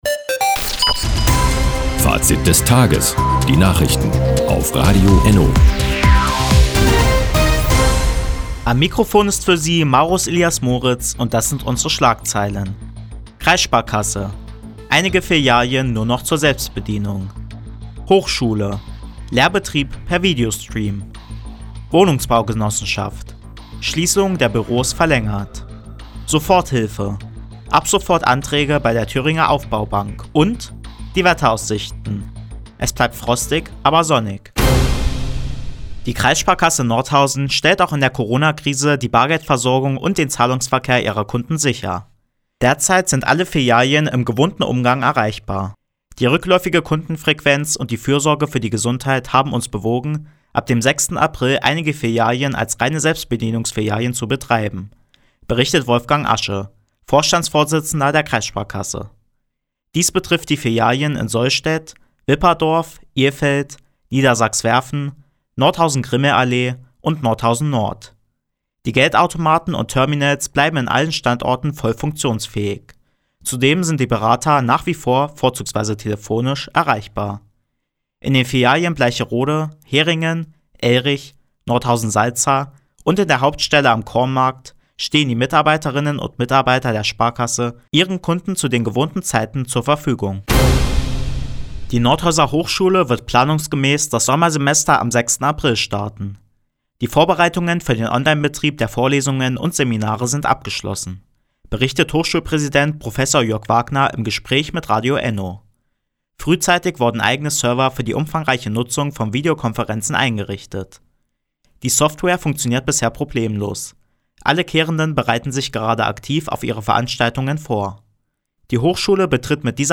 Die tägliche Nachrichtensendung ist jetzt hier zu hören.